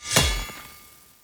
🌲 / foundry13data Data modules soundfxlibrary Combat Single Melee Hit
melee-hit-7.mp3